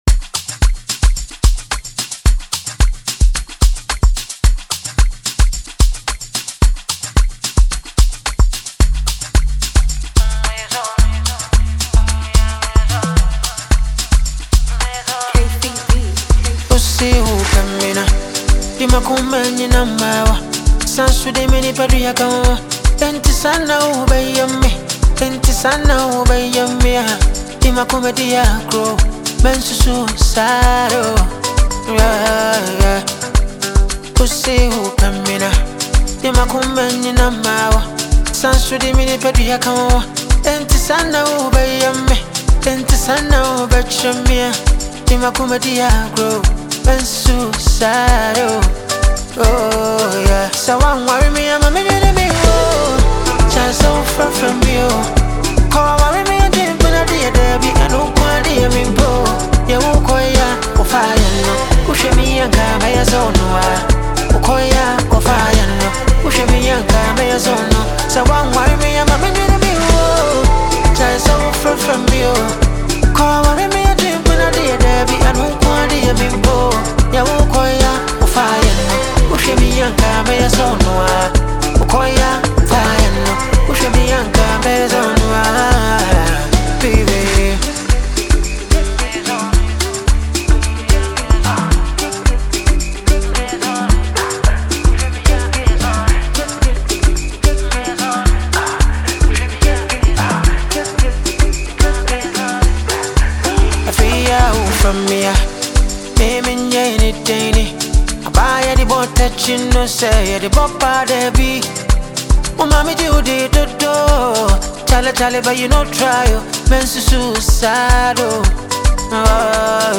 The song’s melody is warm, romantic, and deeply emotional